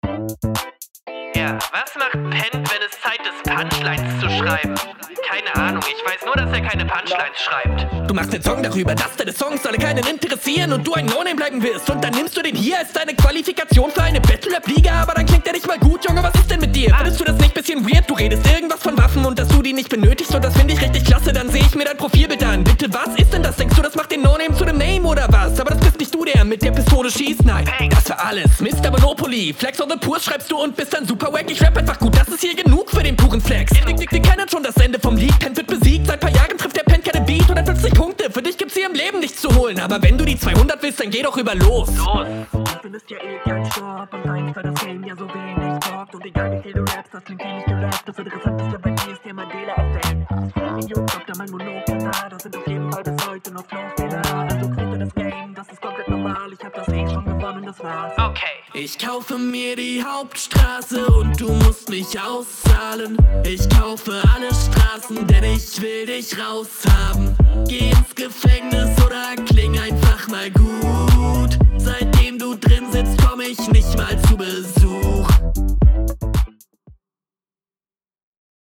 Super gerappt, wird wenige Bronze Runden geben auf dem Raplevel. Die Hook klingt aber ungut, …